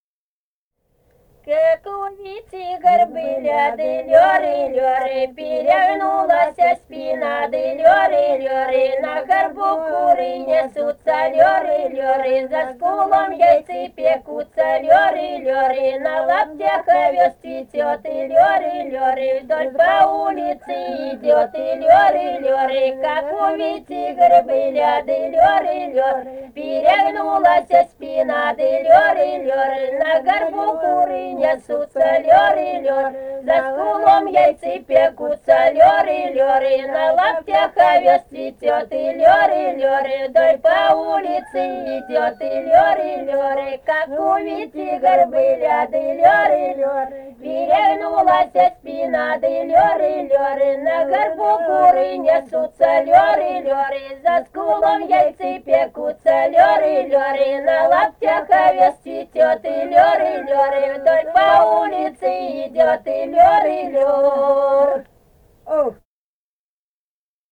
полевые материалы
«Как у Вити-горбыля» (свадебная).
Самарская область, с. Усманка Борского района, 1972 г. И1316-30